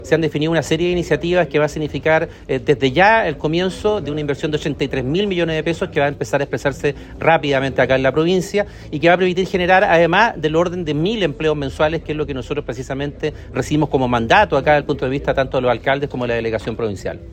El plan de inversiones también contempla iniciativas en el marco del Plan Buen Vivir, con el objetivo de generar un impacto positivo y duradero en el desarrollo productivo y social de las comunidades, explicó el seremi de Obras Públicas, Hugo Cautivo.